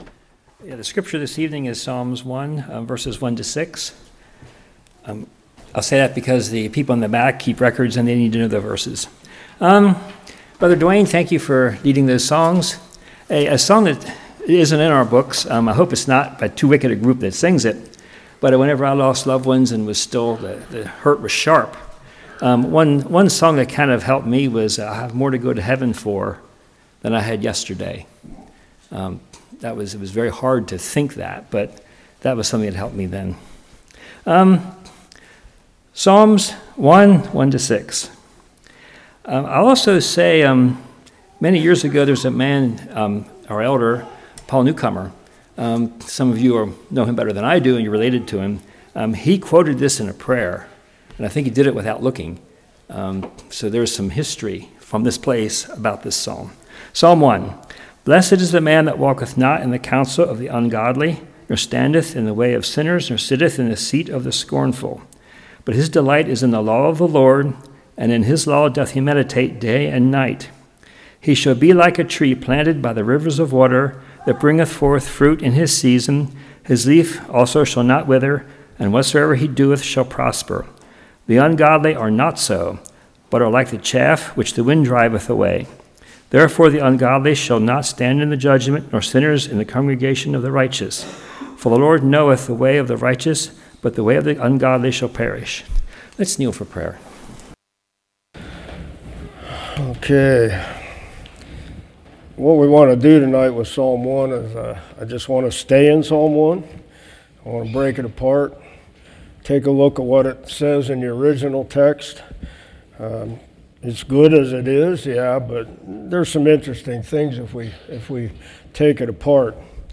Psalm 1:1-6 Service Type: Evening Discussion on the Hebrew word “Blessed.”